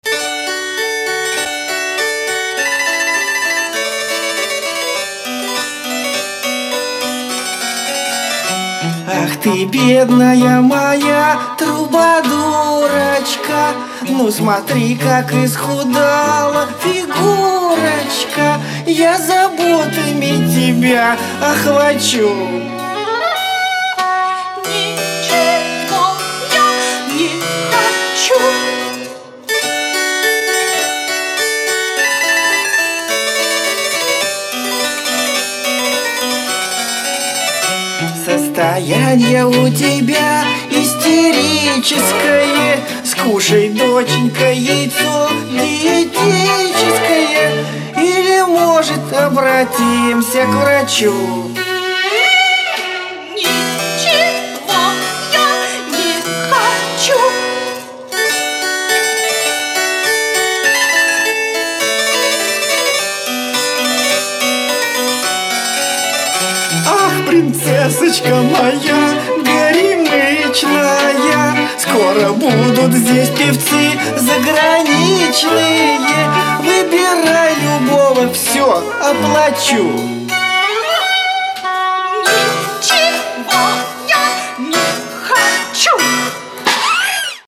Главная » Песни » Песни из русских мультфильмов